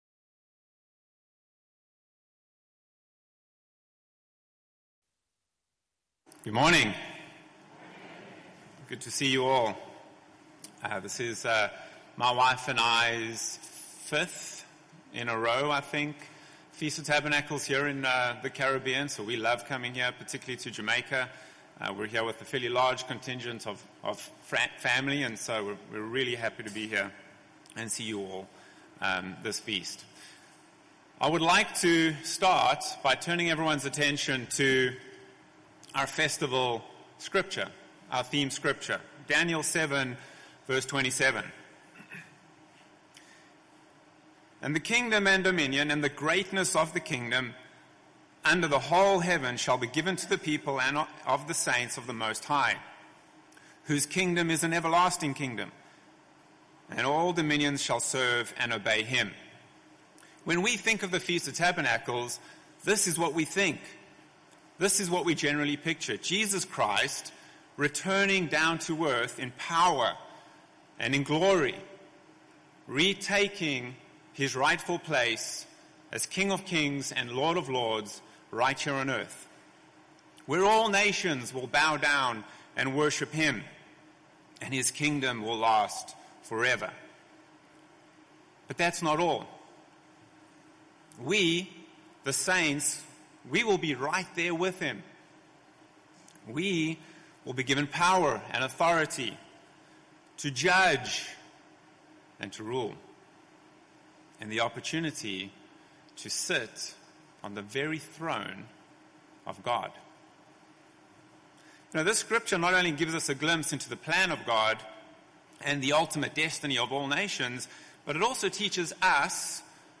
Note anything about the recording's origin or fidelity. This sermon was given at the Montego Bay, Jamaica 2022 Feast site.